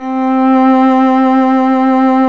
Index of /90_sSampleCDs/Roland - String Master Series/STR_Violin 1 vb/STR_Vln1 % marc